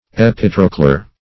Epitrochlear \Ep`i*troch"le*ar\, a.
epitrochlear.mp3